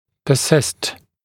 [pə’sɪst][пэ’сист]удерживаться, сохраняться, продолжать существовать